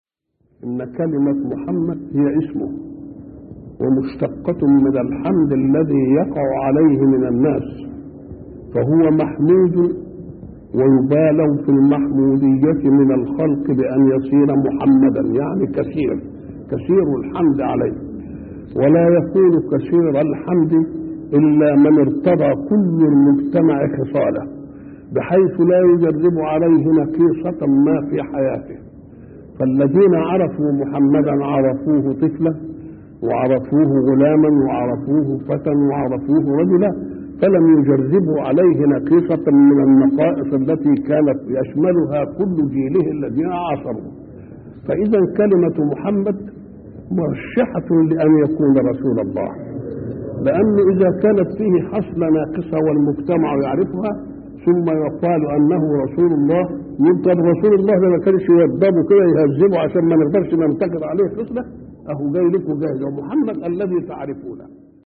شبكة المعرفة الإسلامية | الدروس | اسمه دليل على صدقه |محمد متولي الشعراوي